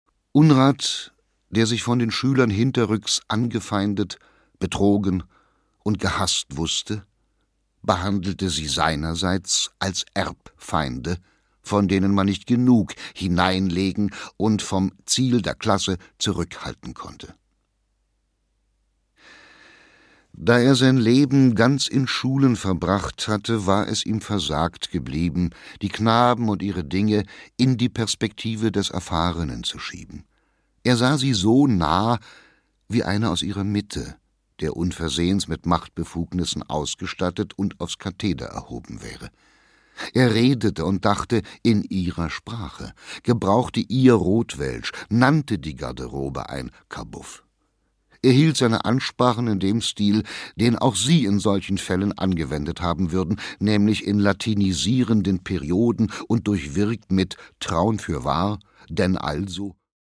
Produkttyp: Hörbuch-Download
Gelesen von: Dieter Mann